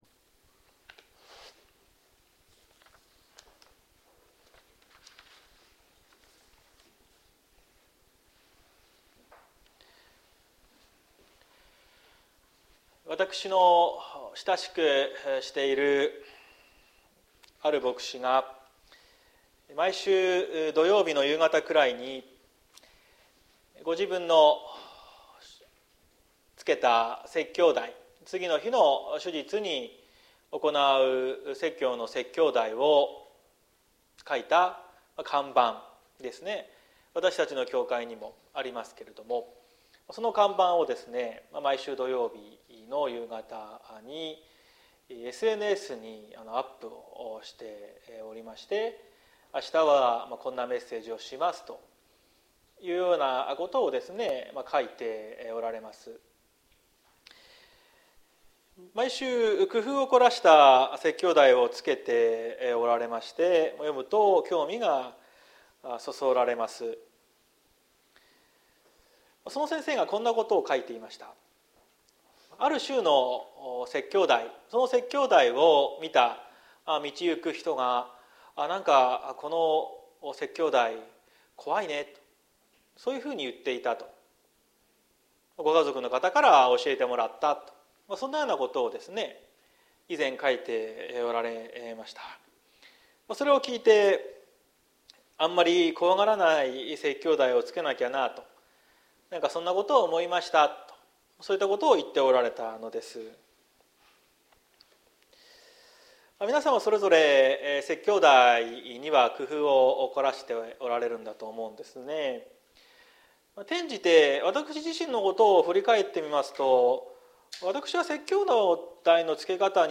2023年11月12日朝の礼拝「キリストがもたらす剣」綱島教会
説教アーカイブ。